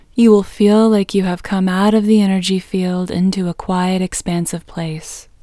IN – the Second Way – English Female 17